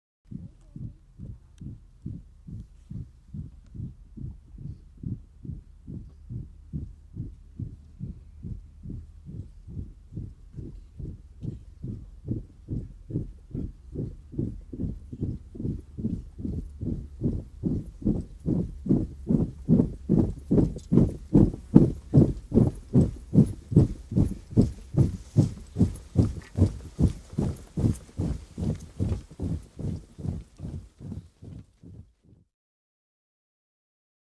Infantry Marches By 1; 200 Men March Up And By On Hard Mud And Grass Surface, Feet Only, Medium To Close Perspective